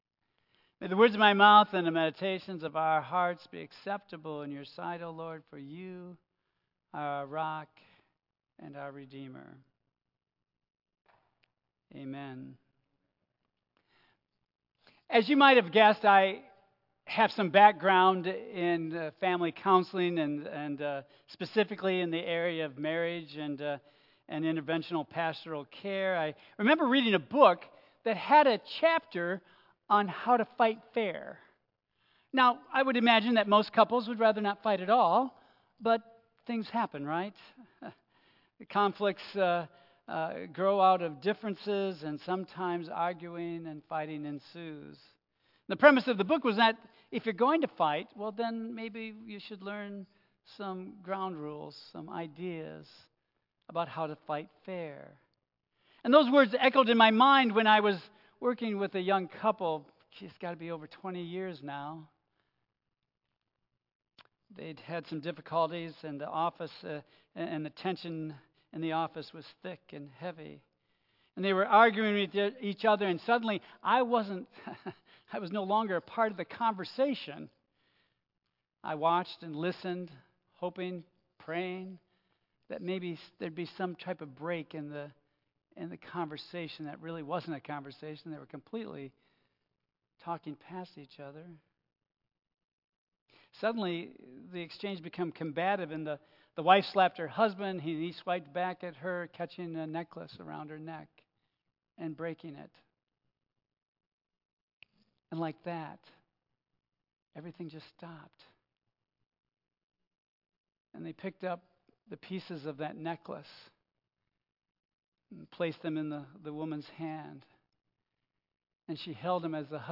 Tagged with Central United Methodist Church , Michigan , Sermon , Waterford , Worship Audio (MP3) 8 MB Previous I Must See Jesus Next Vision for Vitality